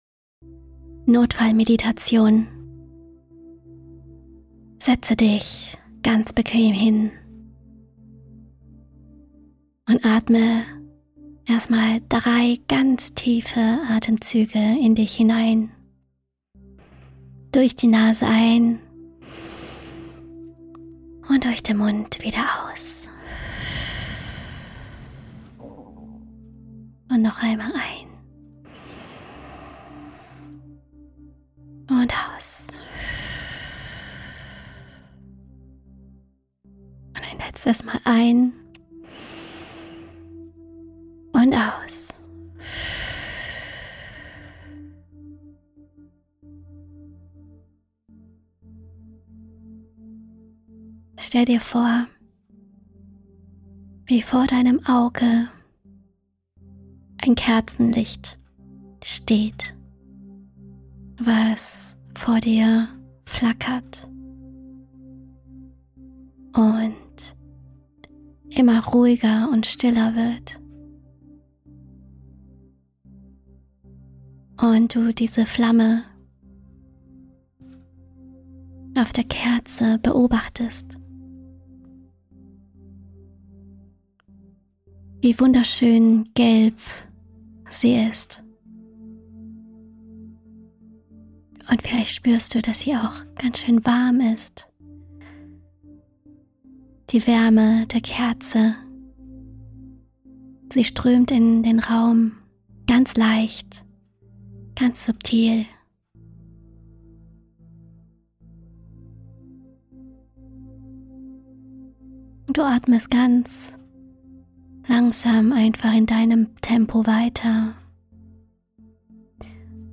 Begleitende Meditation zur Folge 3 – Schlüsselszene